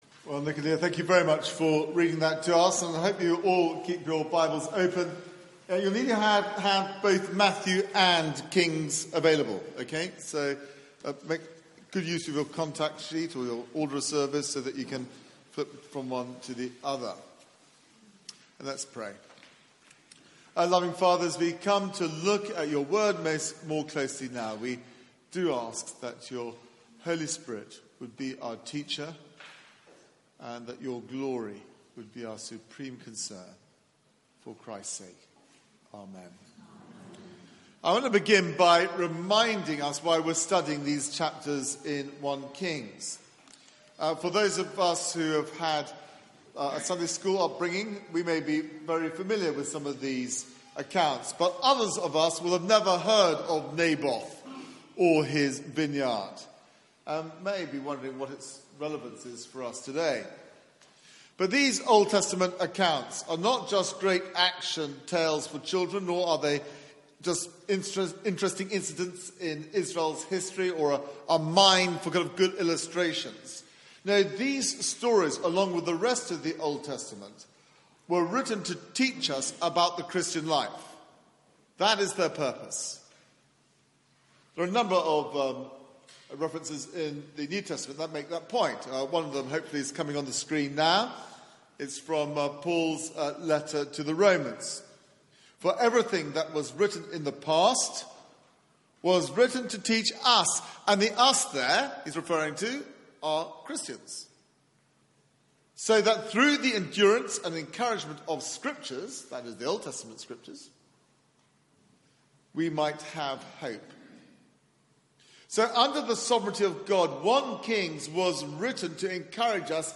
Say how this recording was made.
Media for 9:15am Service on Sun 18th Mar 2018